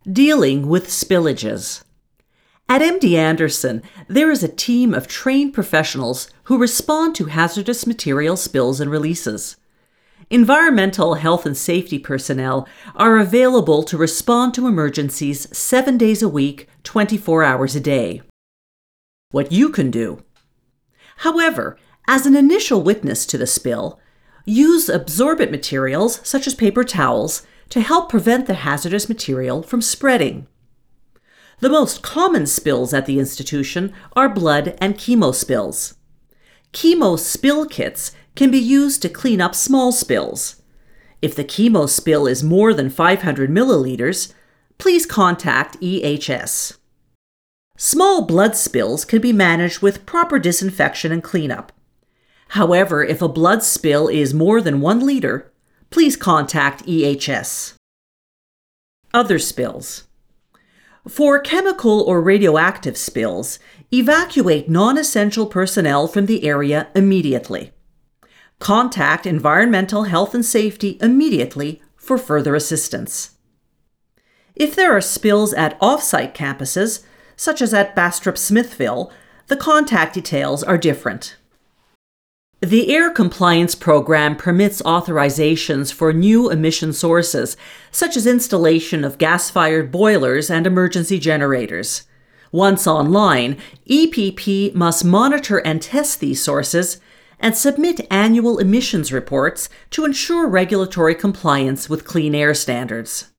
Excerpt from employee training video.